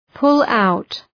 pull-out.mp3